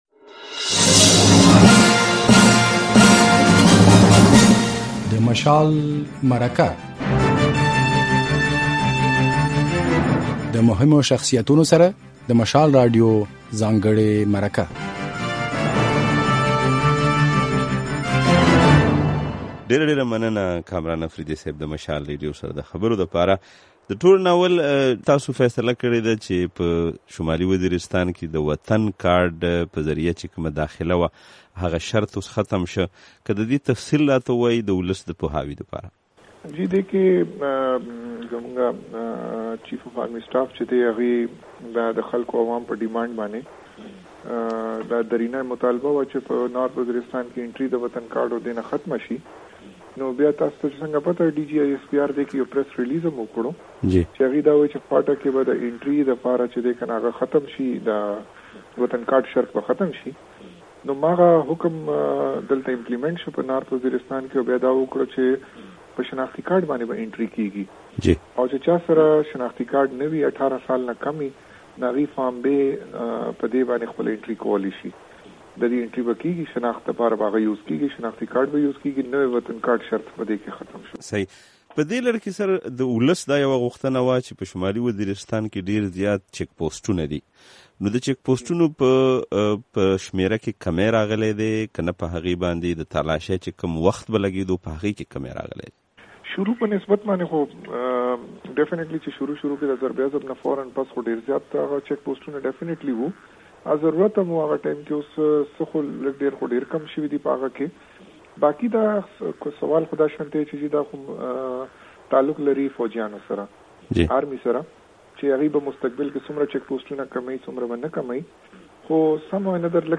د شمالي وزیرستان پر حالاتو له پولیټیکل اېجنټ کامران اپرېدي سره مرکه